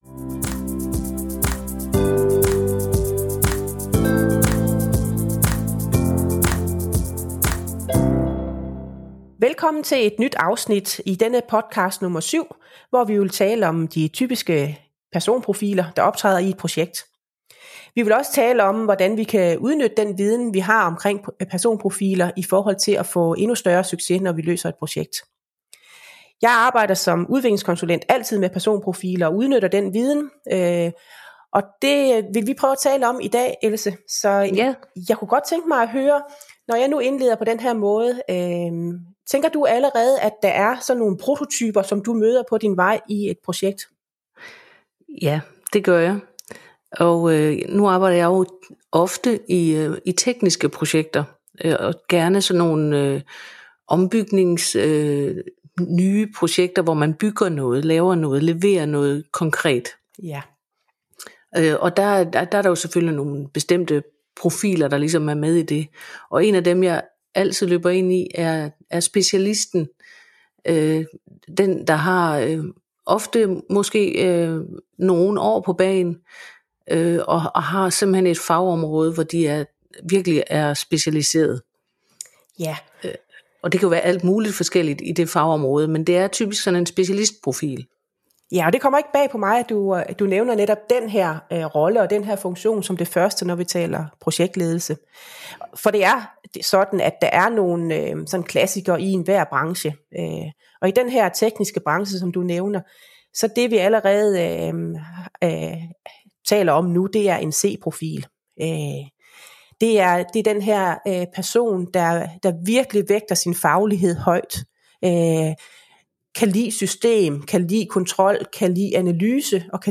taler med projektkonsulent og projektleder